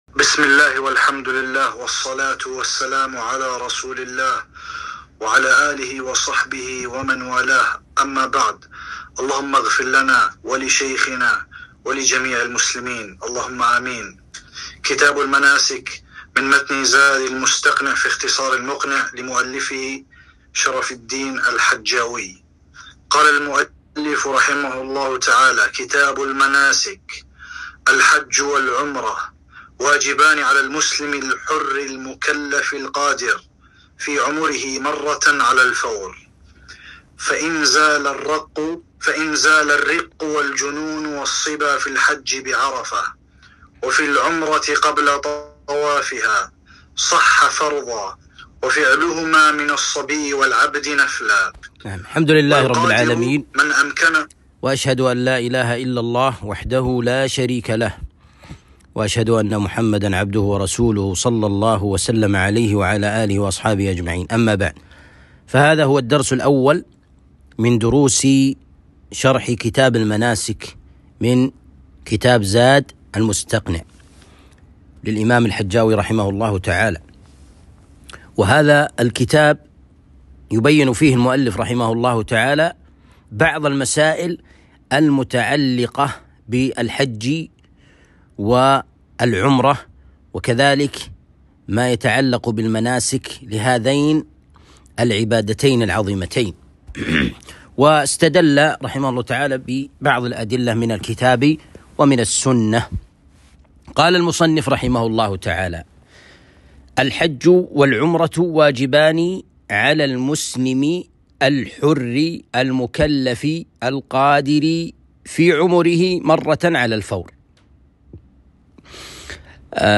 شرح كتاب المناسك من زاد المستقنع - الدرس الأول